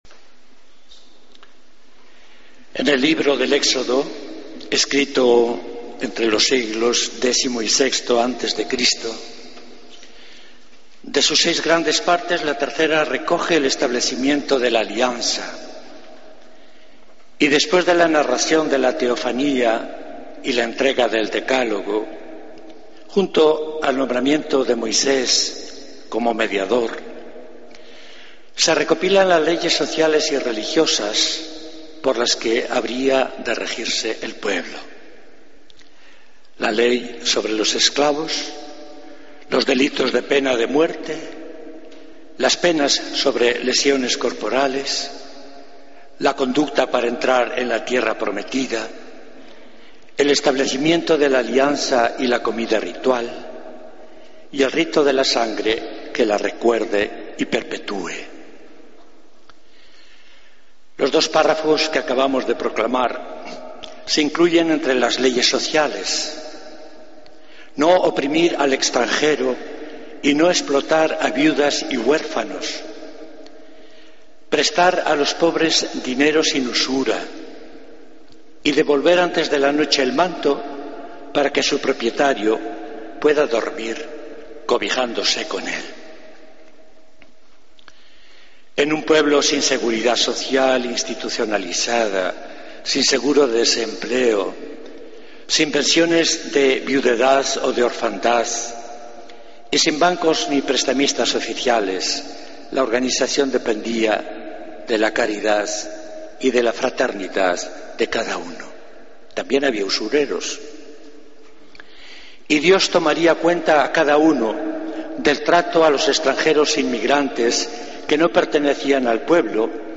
Homilía del Domingo 26 de Octubre de 2014